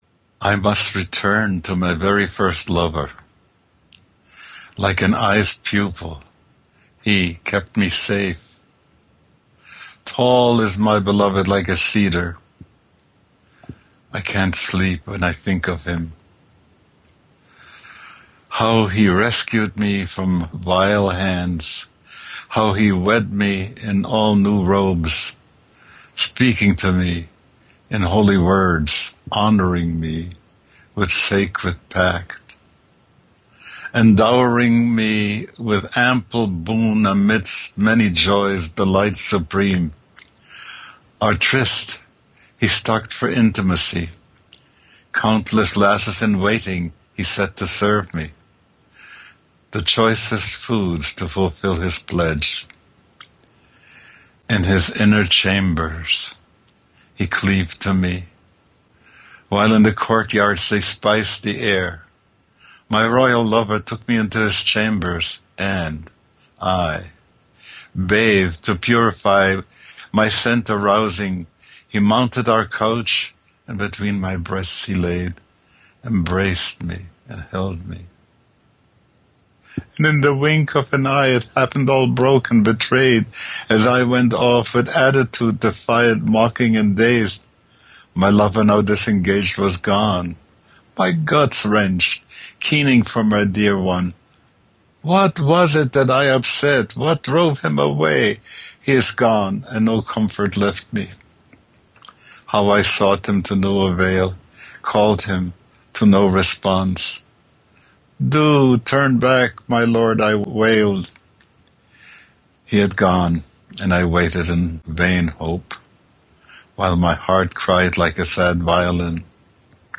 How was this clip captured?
These recordings were not done in the studio under perfect conditions with sophisticated microphones and complicated mixers. They were made over Skype using a Skype recorder and edited using a simple audio editor.